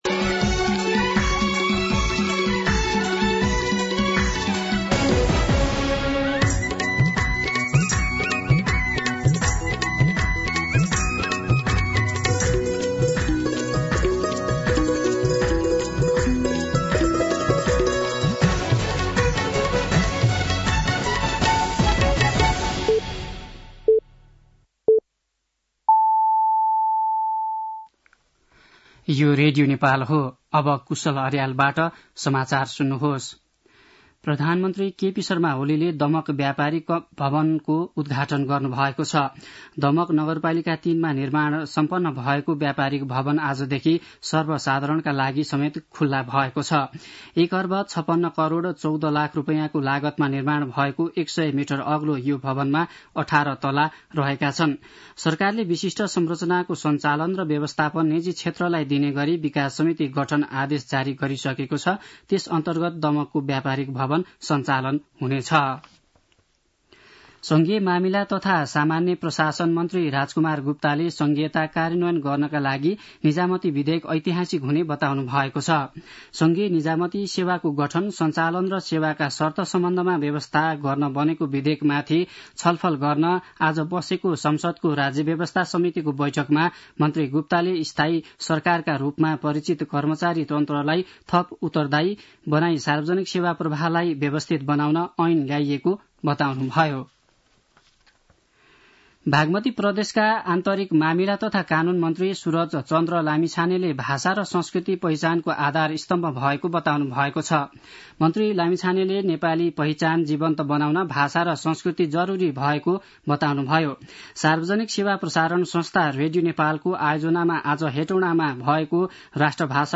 साँझ ५ बजेको नेपाली समाचार : २४ मंसिर , २०८१
5-PM-Nepali-News-8-23.mp3